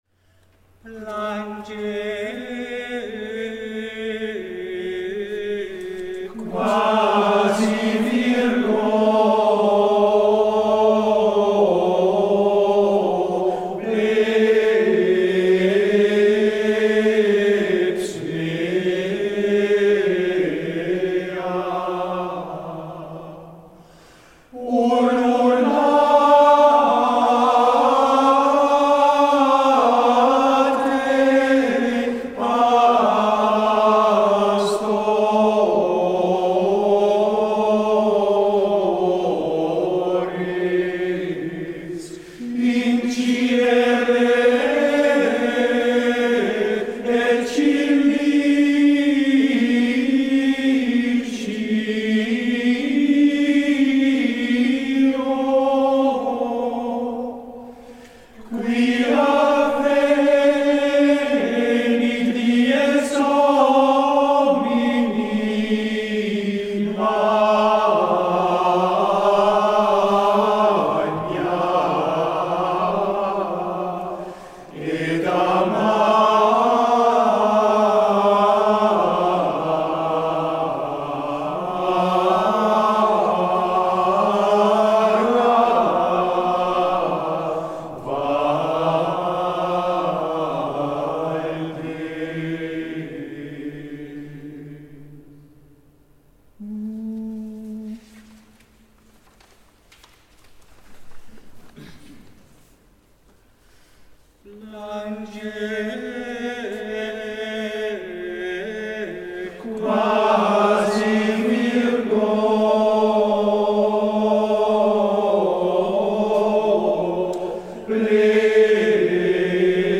Dag van het gregoriaans, Utrecht 9 maart 2019.